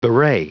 Prononciation du mot bewray en anglais (fichier audio)
Prononciation du mot : bewray